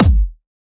Kickin a$s kickdrums " LYR KCK MK4 R1 Marker 8
描述：层次分明，高度处理，具有强烈的攻击力和肥厚的亚音速尾音
Tag: 处理 高度 滚筒 单稳